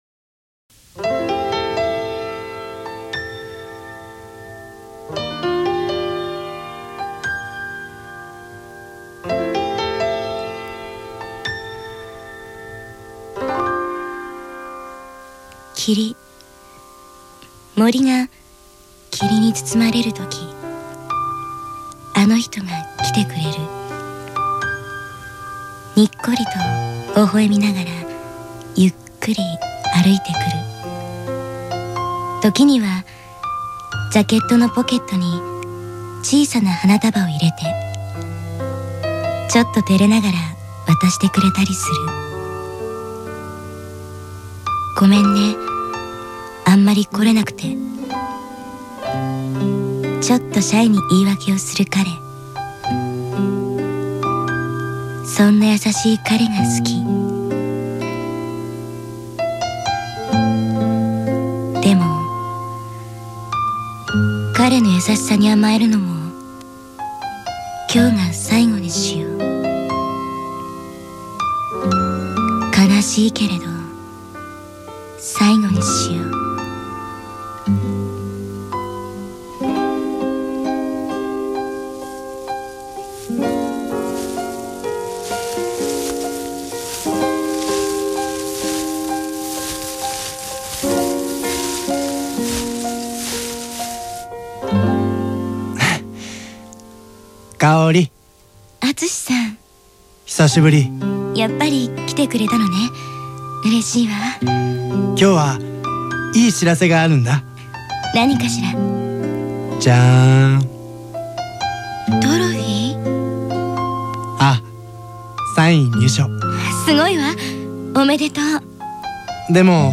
幻想的なクラシック音楽と共に聴けるオーディオドラマ（ラジオドラマ）です。